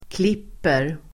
Uttal: [kl'ip:er]